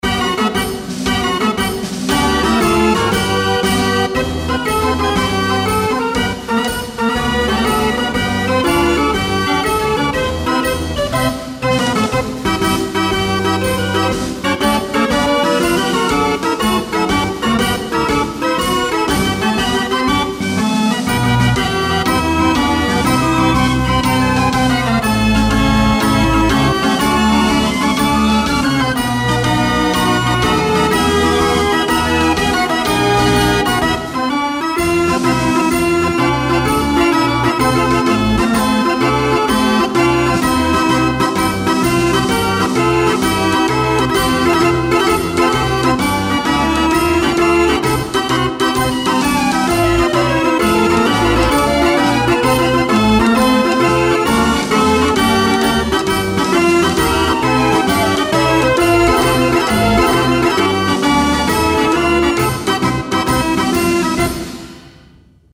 cirque - fanfare - foires